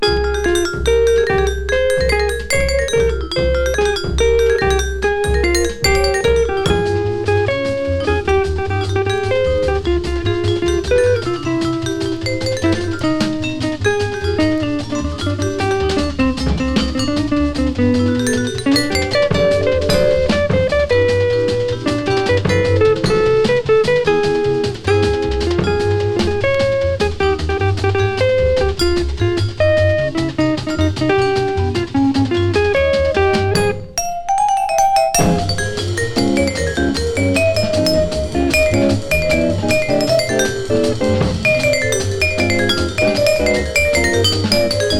Jazz, Bop, Big Band　USA　12inchレコード　33rpm　Mono